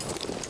Gear Rustle Redone
tac_gear_32.ogg